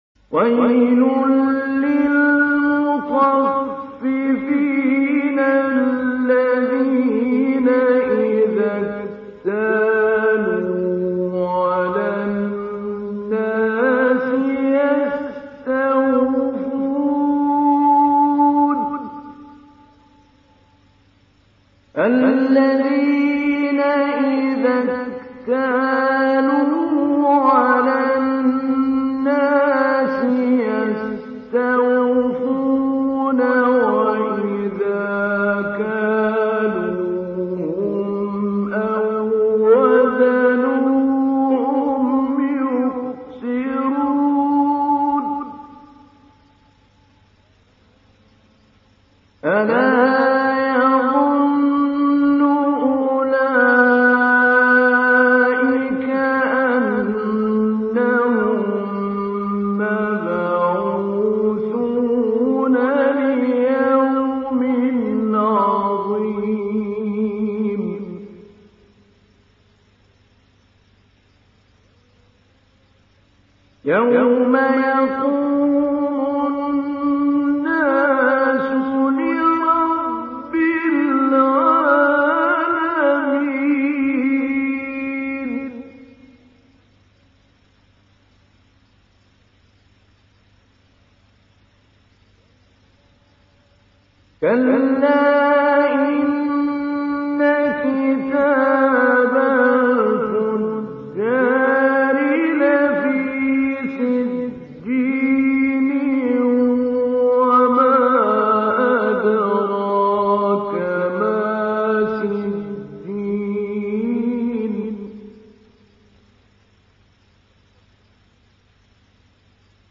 83. Surah Al-Mutaffif�n سورة المطفّفين Audio Quran Tajweed Recitation
Surah Repeating تكرار السورة Download Surah حمّل السورة Reciting Mujawwadah Audio for 83.